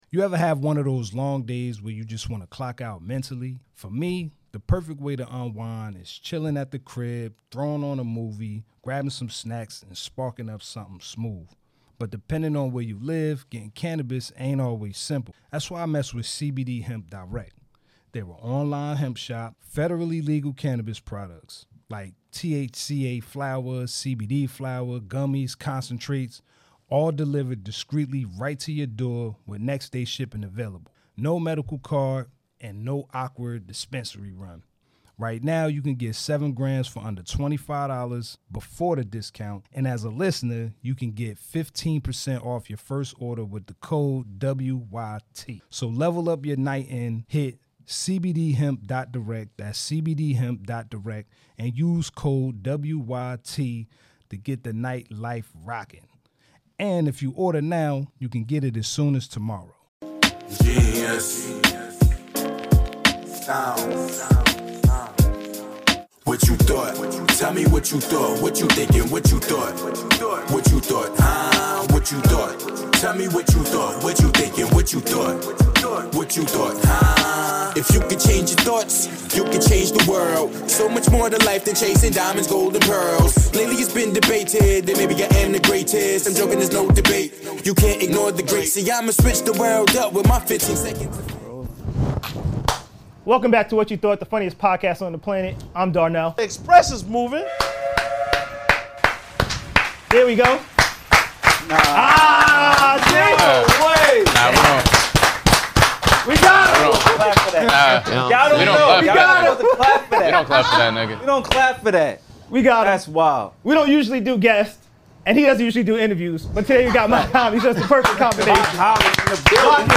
What You Thought | The Mach-Hommy Episode - The Funniest Podcast On The Planet 🌏 Join us for a truly special episode of "What You Thought" as we welcome the elusive and enigmatic Mach-Hommy for one of his rare public appearances and interviews. Known for his intricate lyrics, unique style, and profound storytelling, Mach-Hommy opens up like never before in this exclusive conversation.